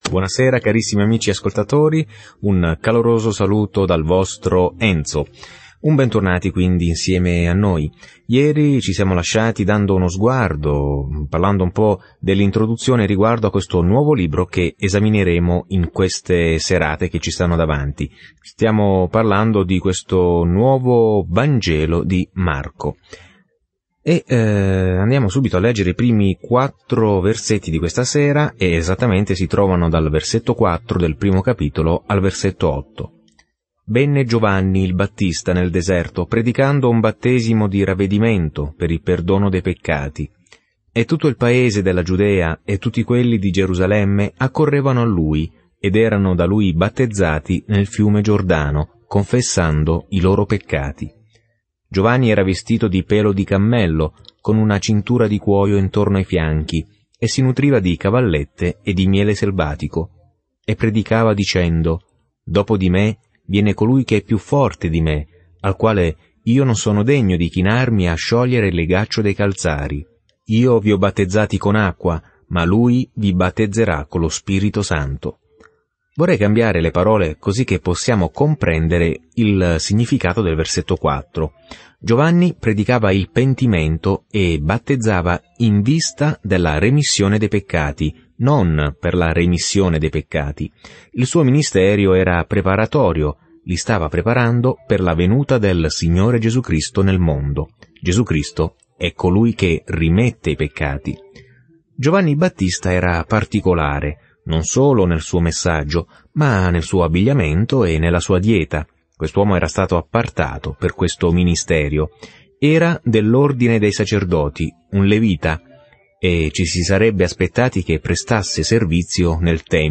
Scrittura Vangelo secondo Marco 1:4-39 Giorno 1 Inizia questo Piano Giorno 3 Riguardo questo Piano Il Vangelo più breve di Marco descrive il ministero terreno di Gesù Cristo come Servo sofferente e Figlio dell’uomo. Viaggia ogni giorno attraverso Marco mentre ascolti lo studio audio e leggi versetti selezionati della parola di Dio.